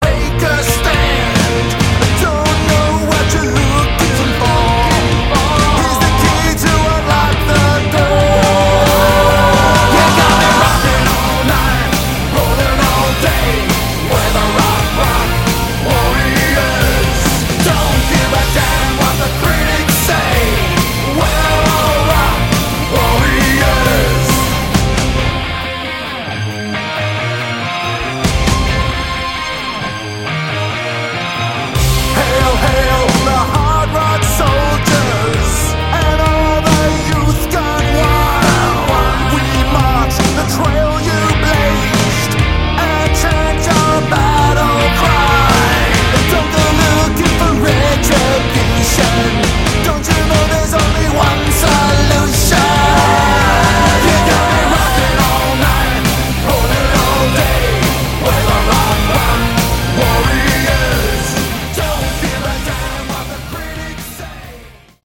Category: Hard Rock
lead vocals, guitars, keys
lead and rhythm guitars
drums
bass